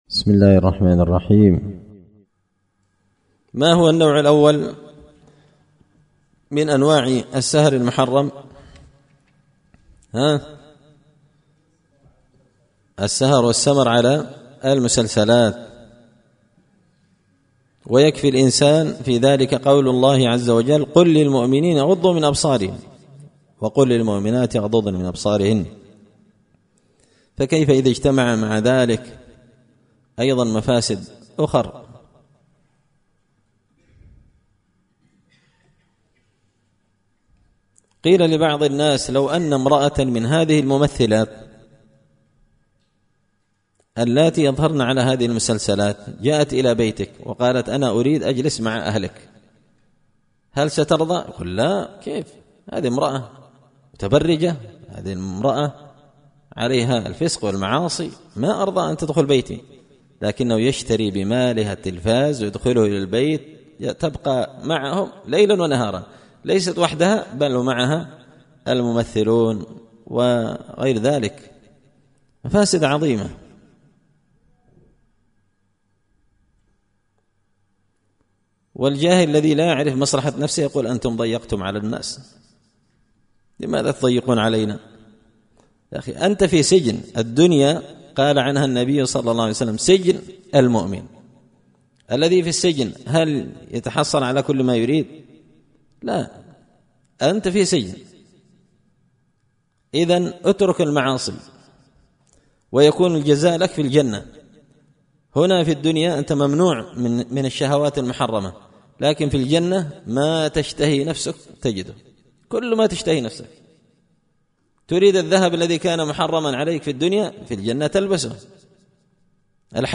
إمتاع النظر بأحكام السمر والسهر ـ الدرس السابع عشر